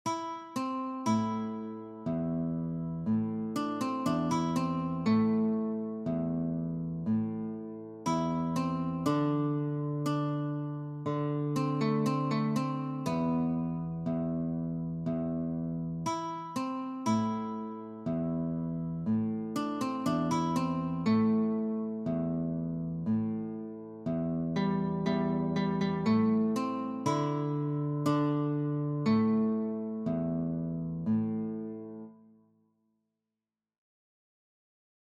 Sololiteratur
Gitarre (1)